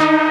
Freq-lead46.ogg